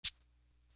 Feel Me Shaker.wav